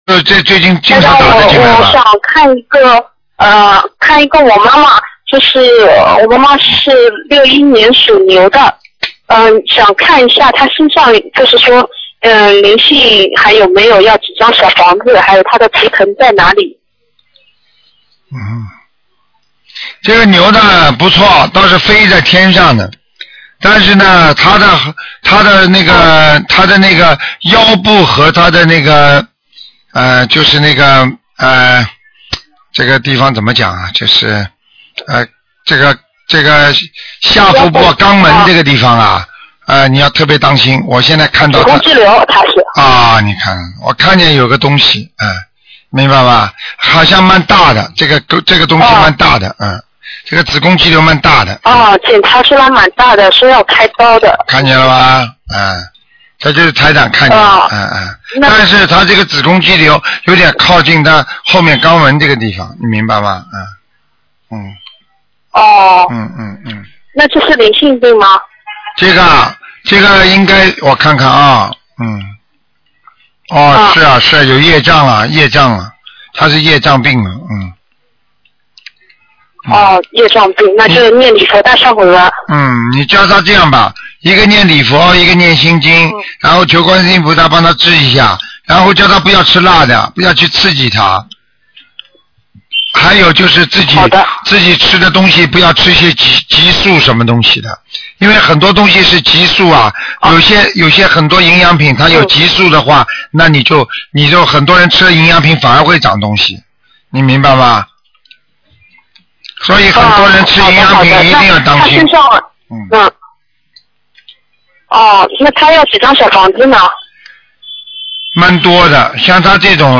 目录：2013年01月_剪辑电台节目录音集锦